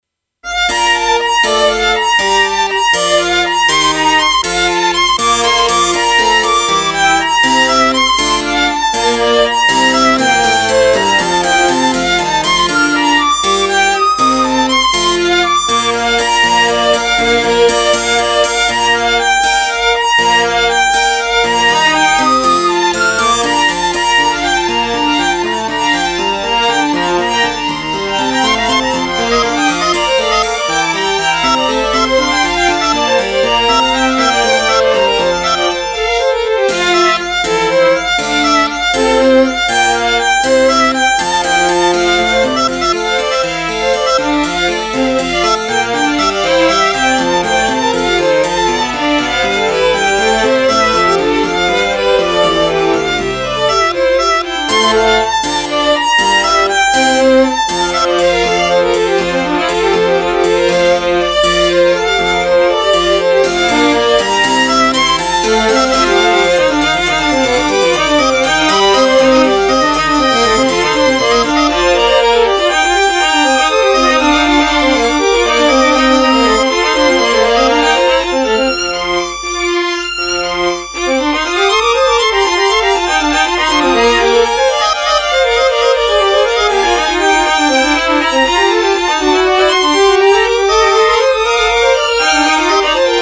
kb-demo violini 2.mp3